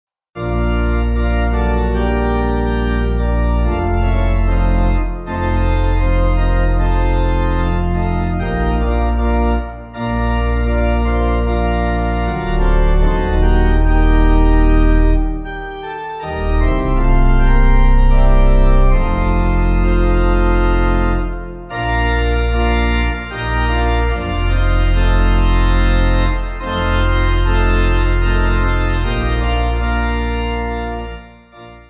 Organ
(CM)   4/Bb